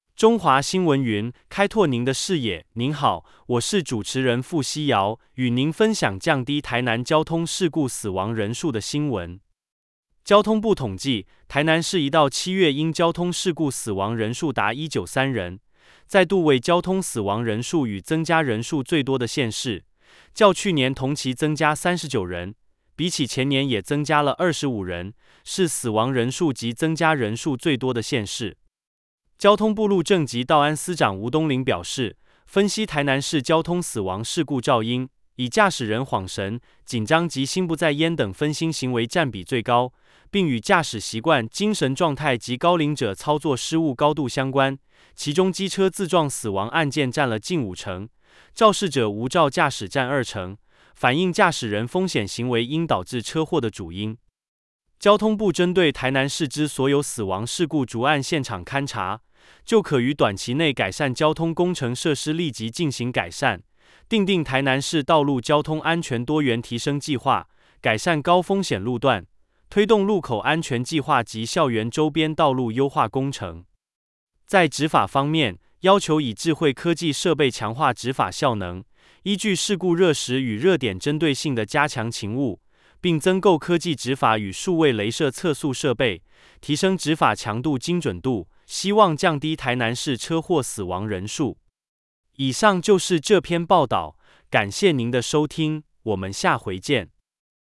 台南市車禍死亡人數最多（語音新聞）